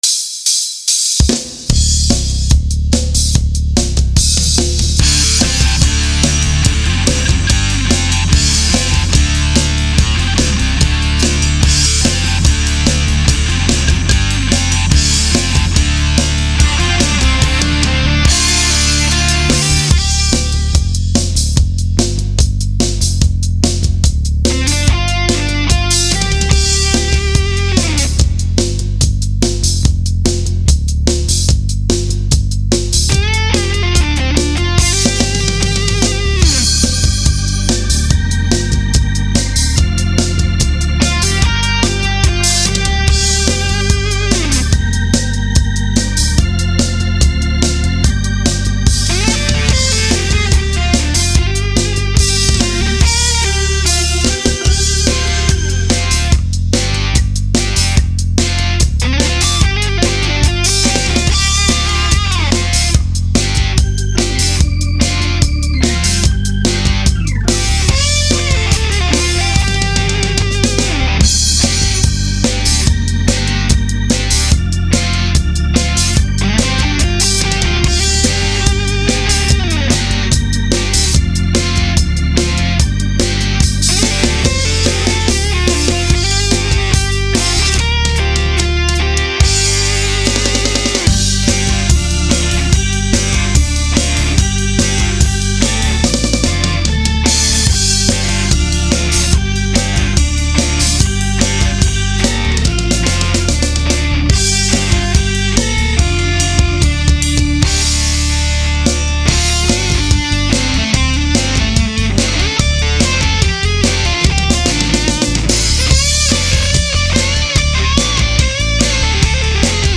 Lead & Power Guitar
Ντράμς
Πλήκτρα, Μπάσο